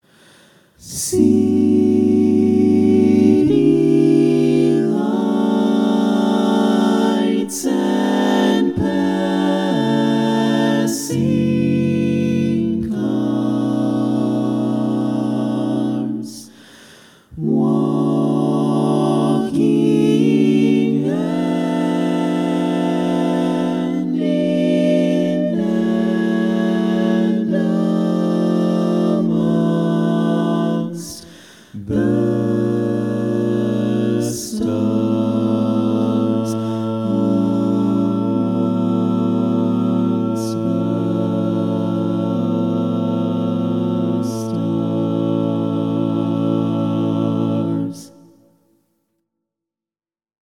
Key written in: A Major
How many parts: 5
Type: Barbershop
Comments: Some 5 part jazz because why not
All Parts mix:
Learning tracks sung by